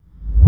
Track 08 - Reverse Kick OS 01.wav